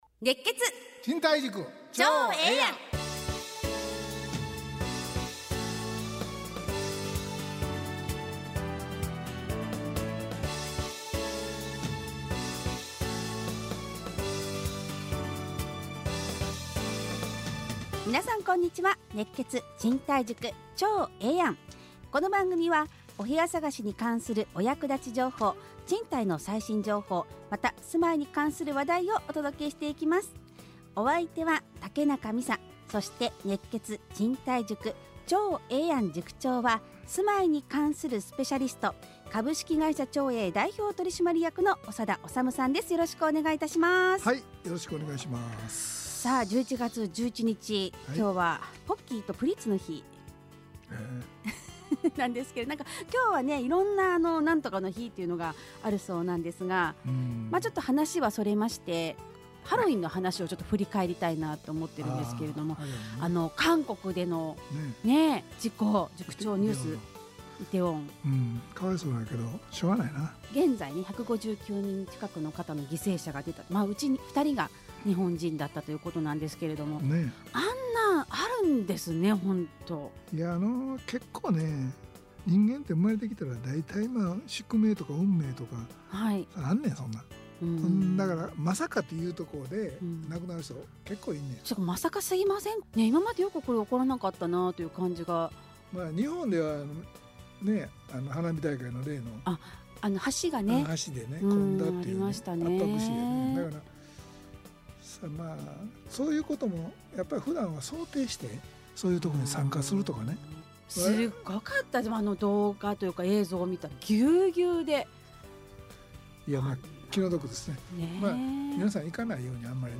ラジオ放送 2022-11-14 熱血！